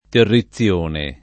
terrizione [ terri ZZL1 ne ] s. f. (giur.)